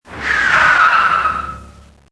B_FREINE.mp3